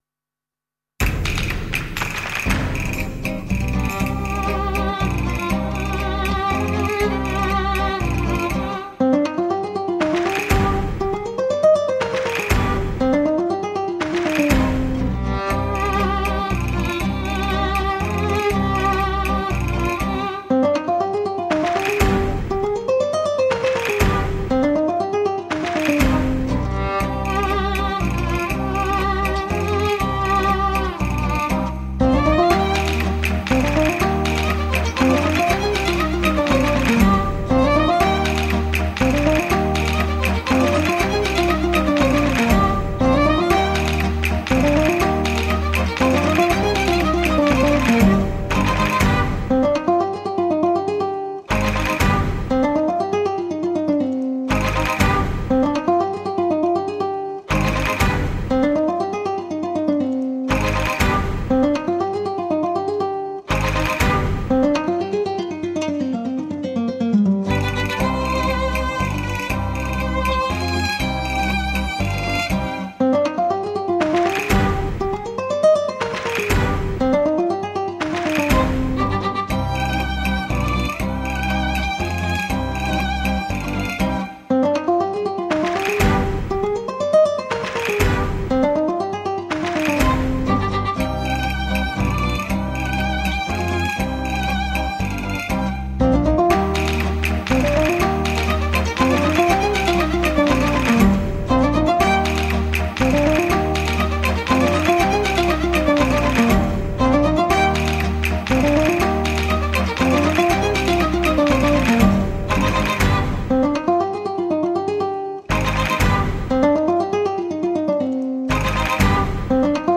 抒情、展技无所不用其极
沉稳凝练的演绎 形神兼备
HiFi吉他典范
传统又具韵味，诠释功夫一流，生动又感人。